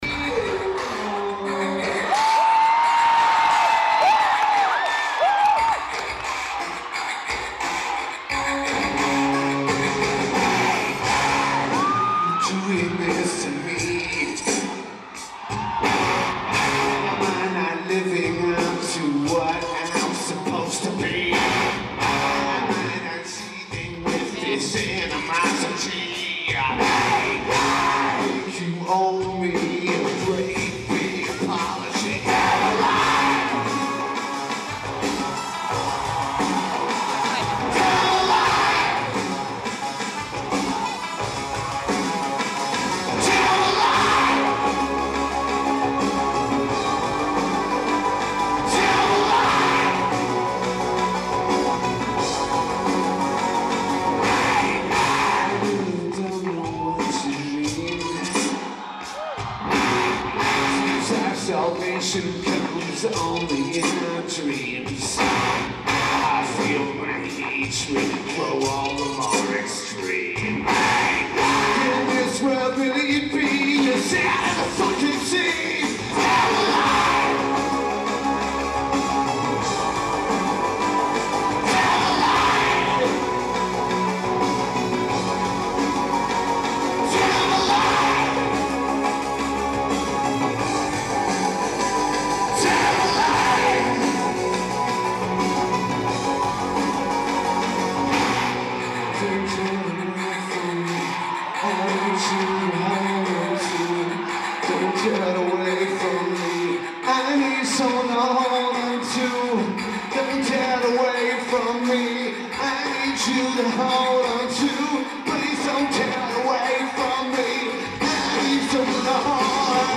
Pacifico Hall
Yokohama Japan
Lineage: Audio - AUD, Unknown Audio from Video Recording